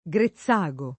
[ g re ZZ#g o ]